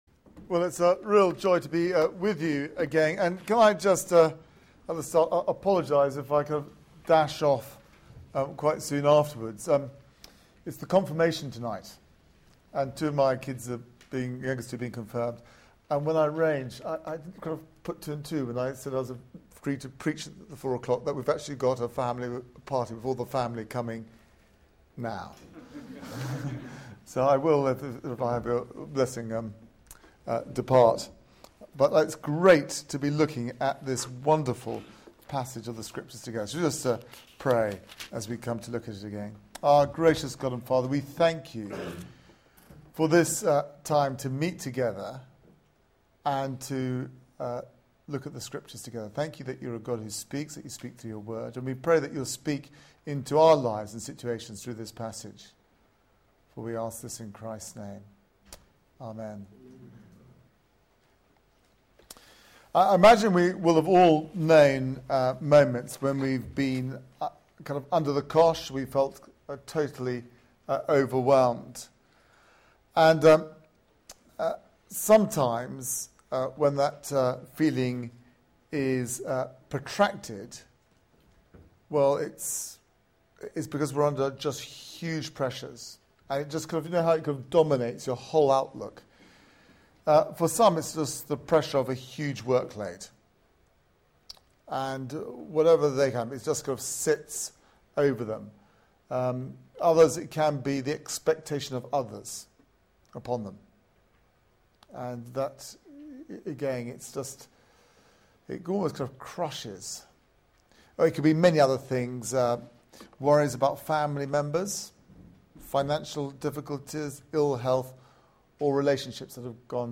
Media for 4pm Service on Sun 22nd Apr 2012 16:00 Speaker
Sermon